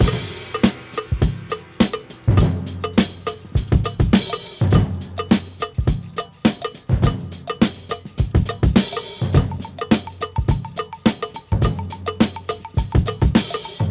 • 138 Bpm Modern Jazz Breakbeat Sample B Key.wav
Free drum beat - kick tuned to the B note. Loudest frequency: 1549Hz
138-bpm-modern-jazz-breakbeat-sample-b-key-0LI.wav